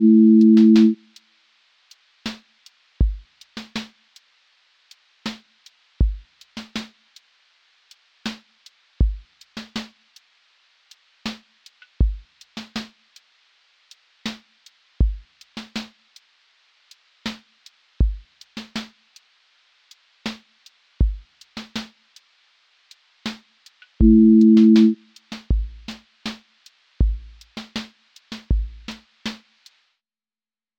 QA Listening Test lofi Template: lofi_crackle_sway
• macro_lofi_core
• voice_kick_808
• voice_snare_boom_bap
• voice_hat_rimshot
• texture_vinyl_hiss
• tone_warm_body
• fx_space_haze_light
• voice_sub_pulse